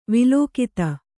♪ vilōkita